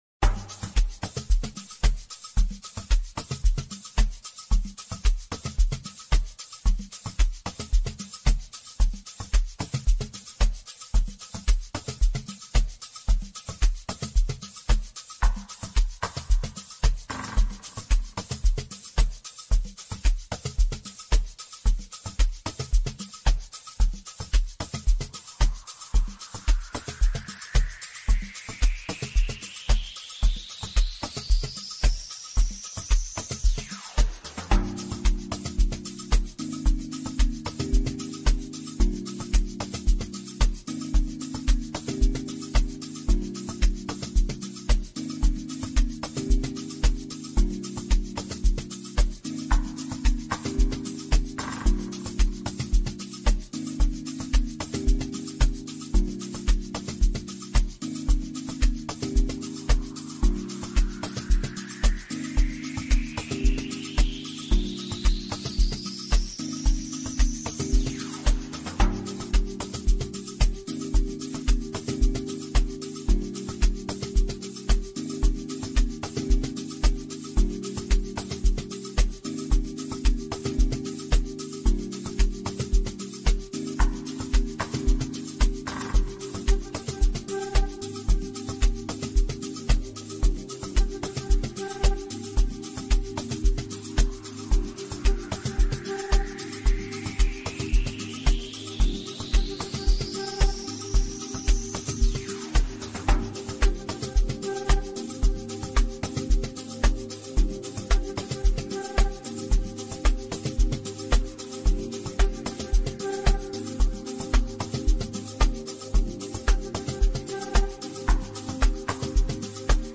His songs are always soulful and appealing to the ears.
He plays a soulful song for us.